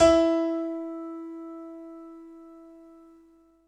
11 SY99 Piano E4.wav